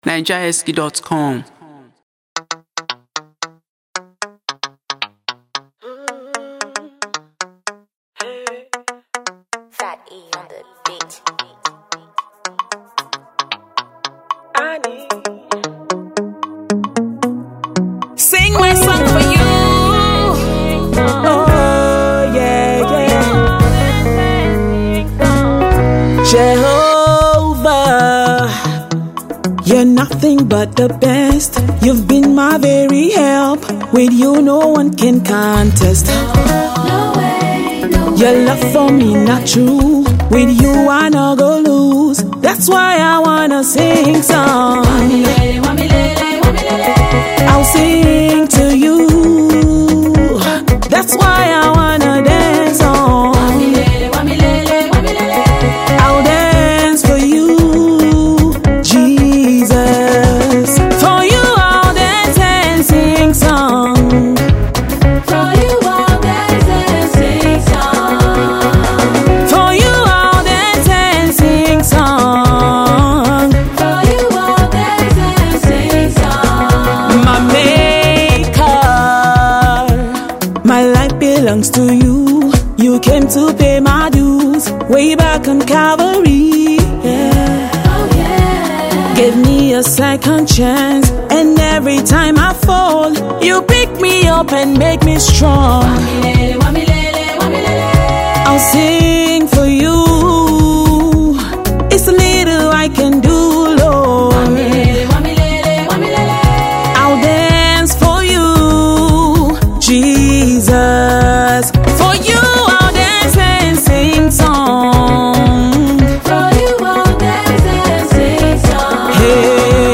Gospel sensation and inspirational singer
sweet and melodious tune
The song is glorifying, spirit lifting and a good follow up!